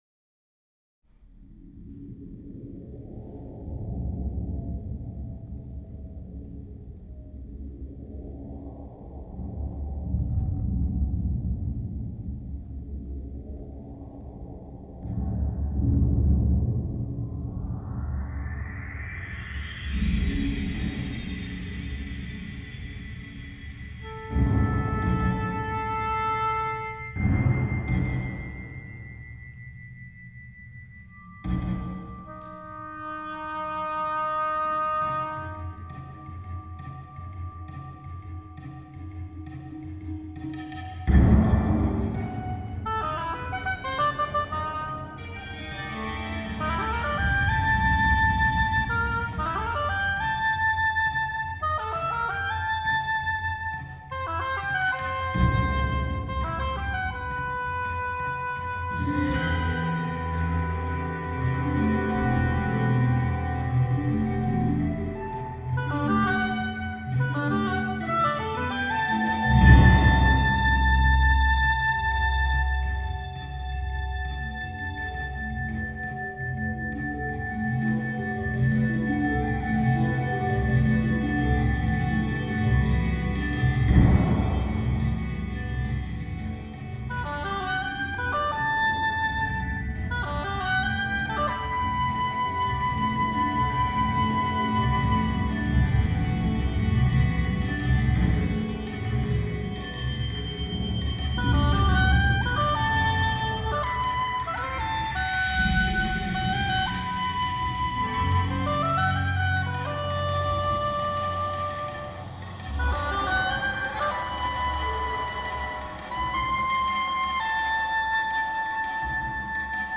oboe solo and electronic tape
oboe soloist